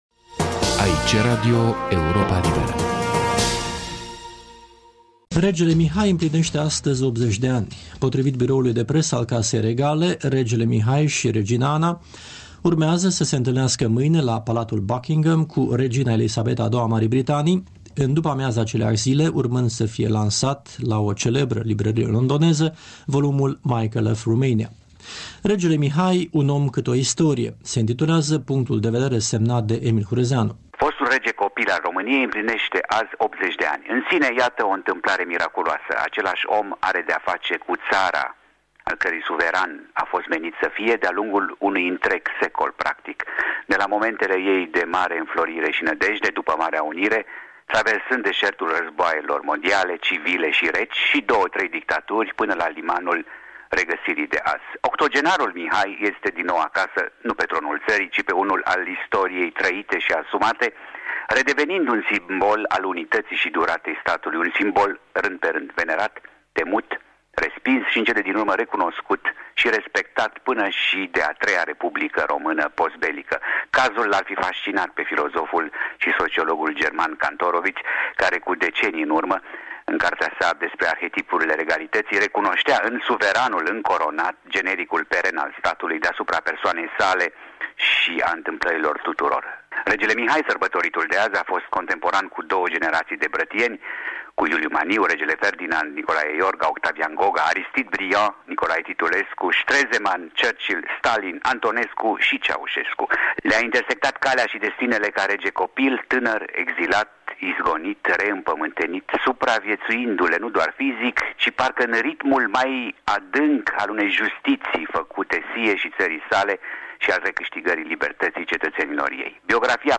Un comentariu difuzat la Radio Europa Liberă în 25 octombrie 2001, când Regele Mihai împlinea 80 de ani.